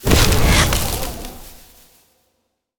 nature_spell_vines_whoosh_03.wav